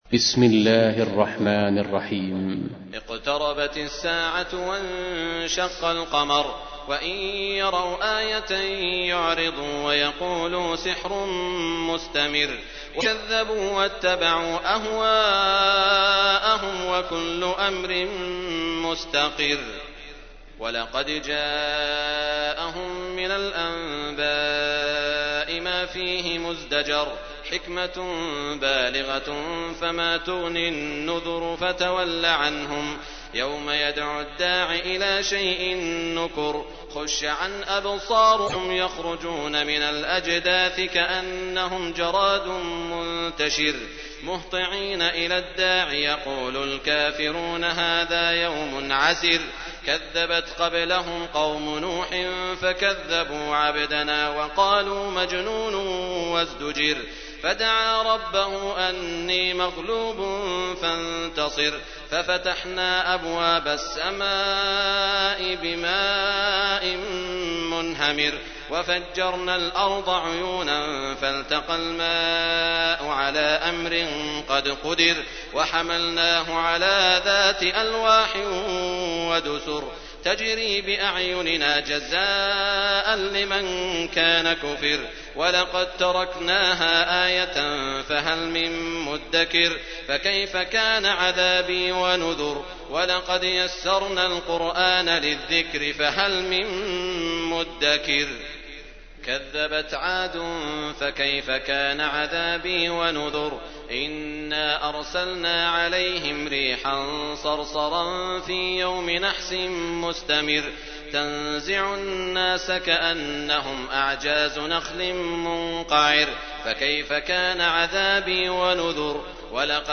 تحميل : 54. سورة القمر / القارئ سعود الشريم / القرآن الكريم / موقع يا حسين